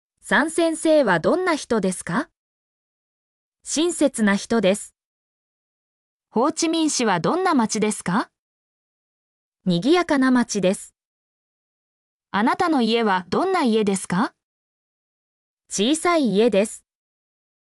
mp3-output-ttsfreedotcom-45_PZHIxFCr.mp3